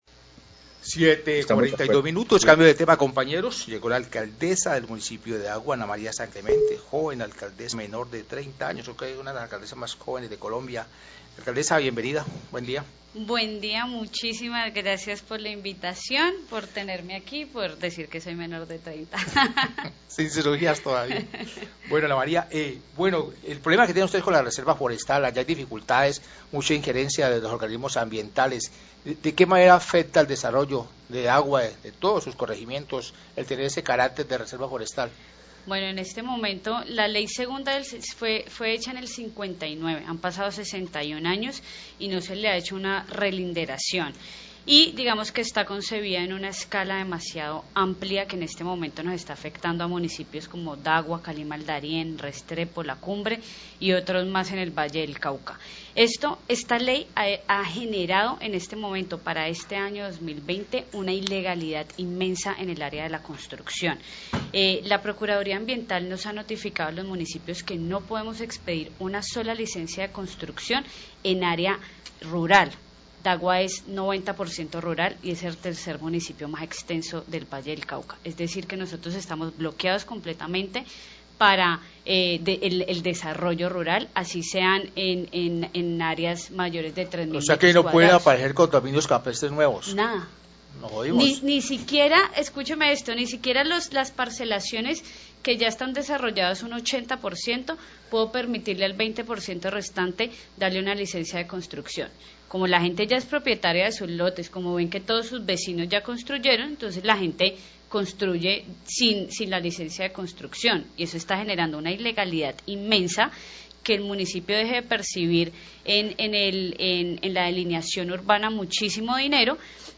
Alcaldesa de Dagua sobre el problema de no poder expedir licencias de construcción en el municipio, Noticiero Relámpago, 744am
Radio